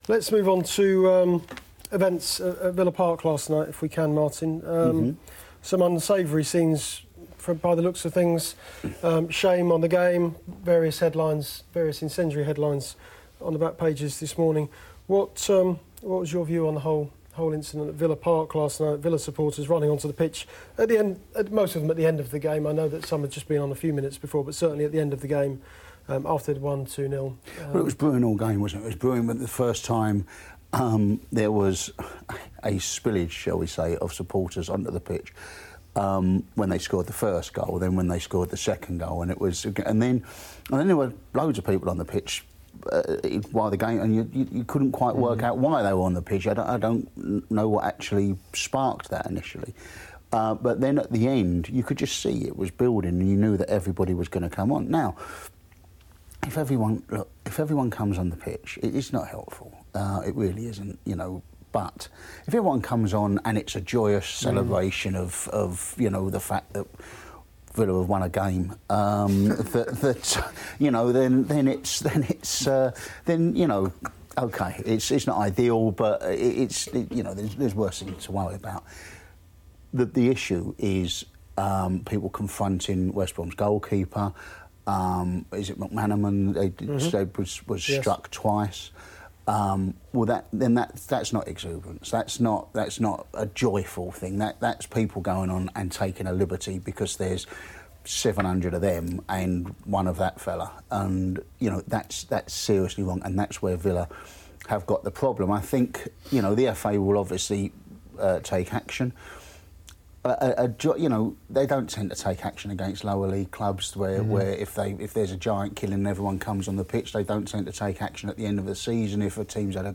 The Sunday Supplement panel discuss the unsavoury scenes at Villa Park at the end of Aston Villa’s cup tie with West Brom.